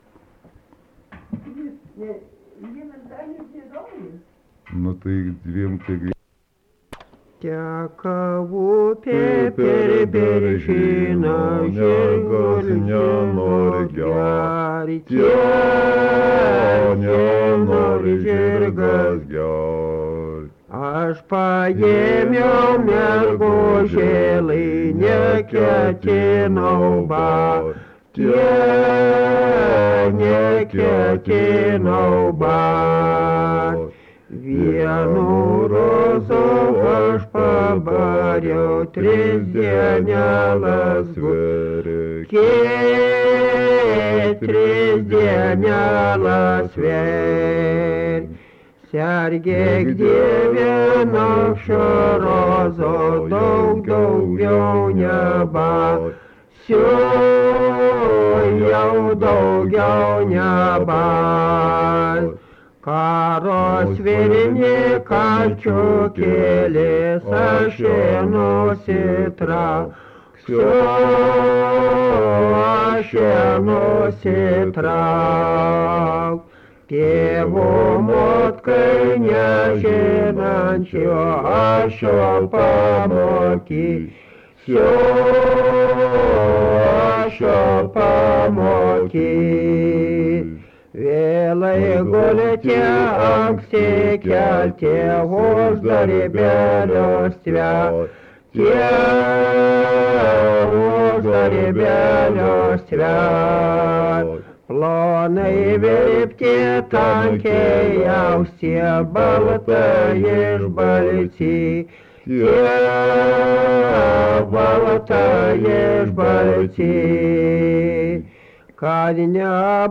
Dalykas, tema daina
Erdvinė aprėptis Raitininkai
Atlikimo pubūdis vokalinis